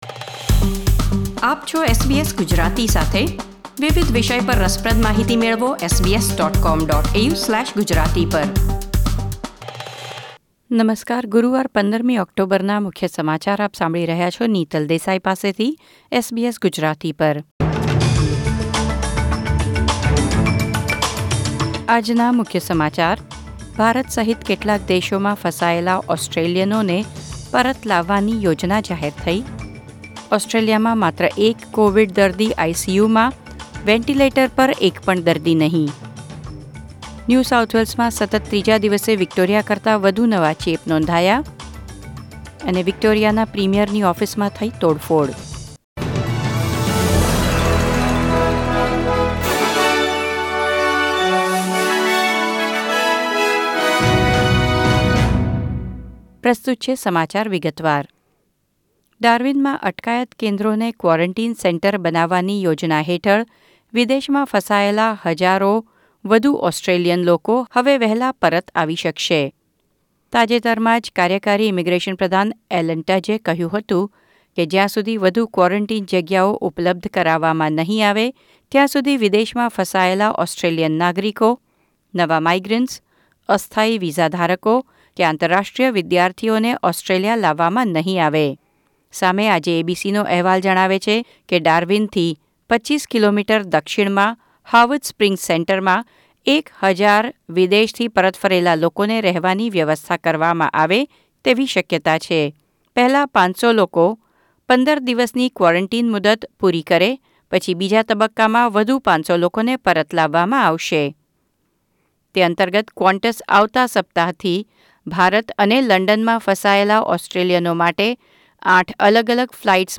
SBS Gujarati News Bulletin 15 October 2020